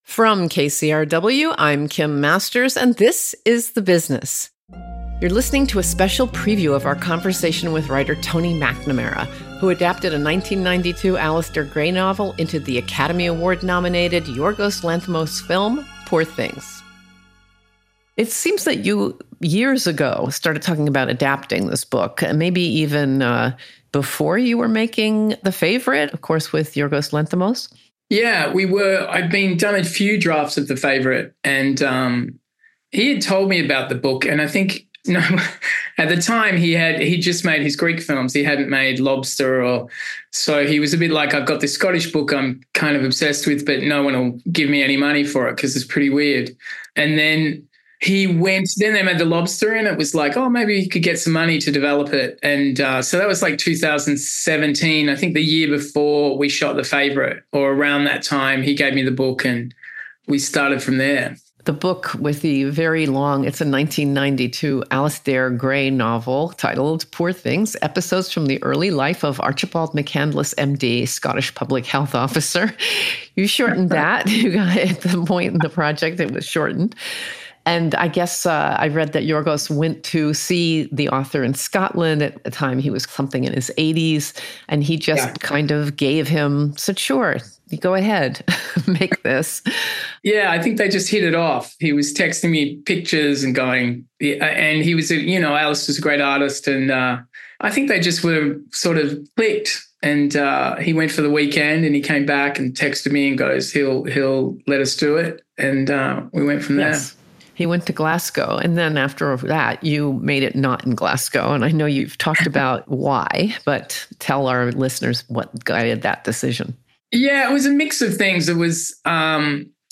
PREVIEW: Kim Masters speaks to writer Tony McNamara, who adapted a 1992 Alasdair Gray novel into the Oscar nominated Yorgos Lanthimos film, Poor Things. Tune in to The Business on March 8th to hear the full interview with McNamara, where he shares how his toddler inspired him to write Emma Stone’s favorite line in the Academy Award nominated screenplay.